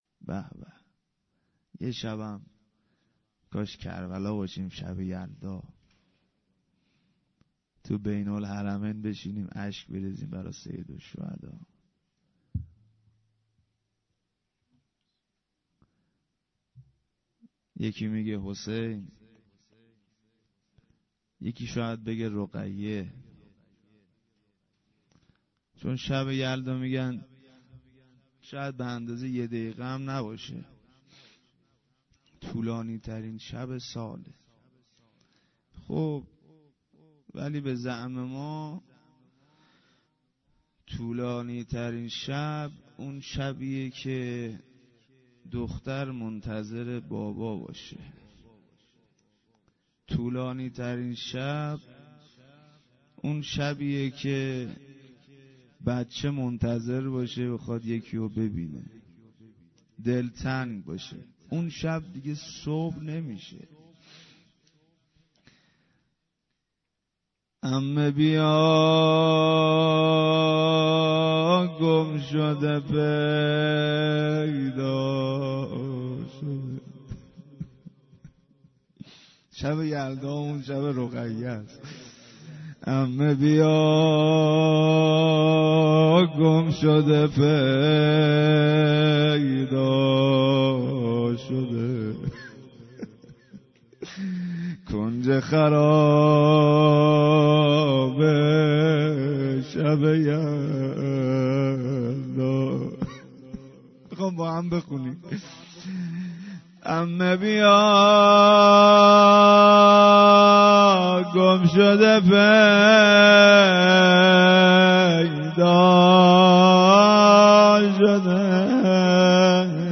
وفات حضرت معصومه-جمعه30آذر97
هیئت زواراباالمهدی(ع) بابلسر